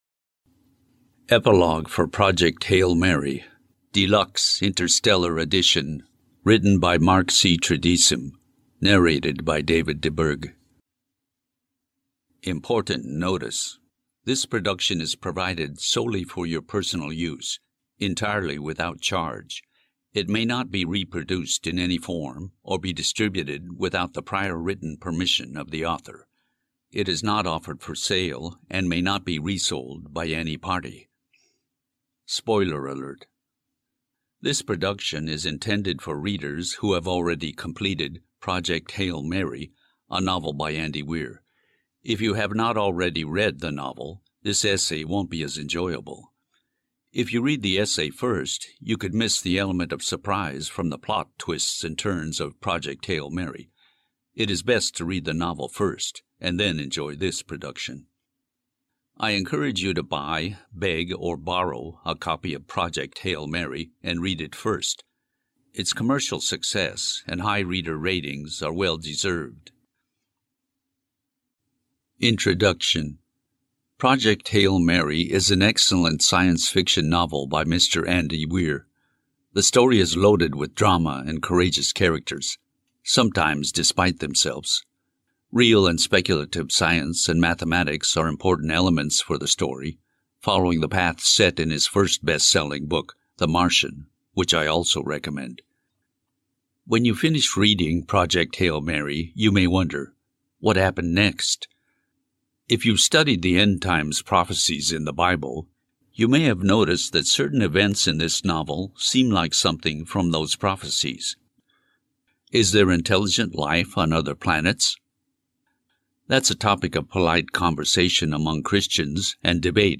Audio Book.